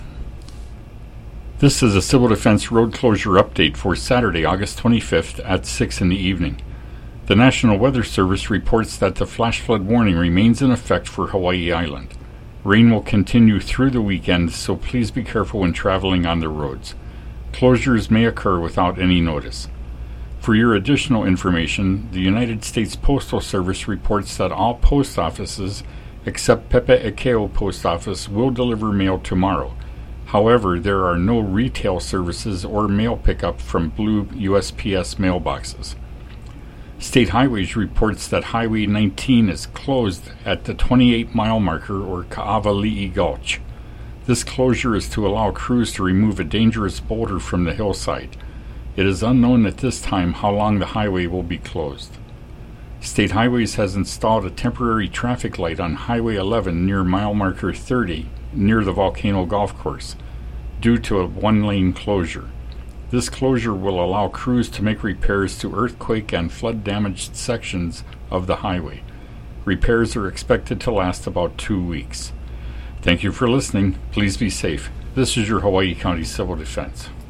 Hawaii County Civil Defense audio message